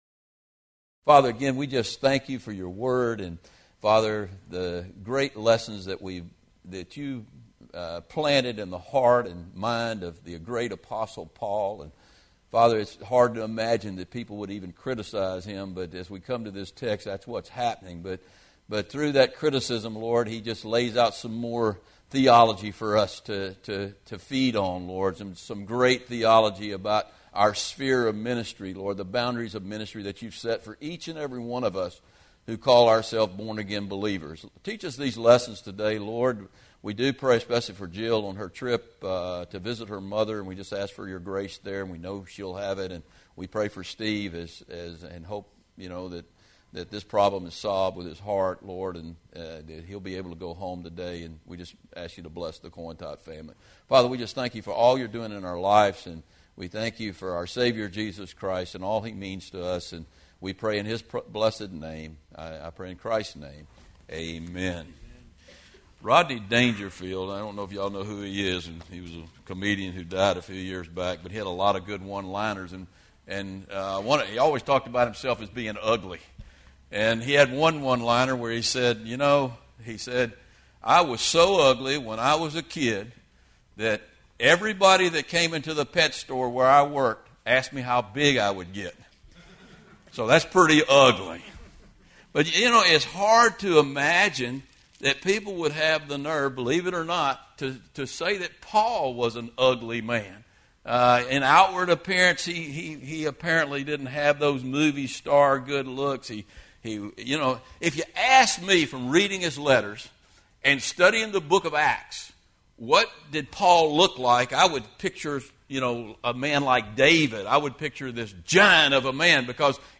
2 Corinthians 10:7-18 & Lord’s Supper – Our Sphere of Ministry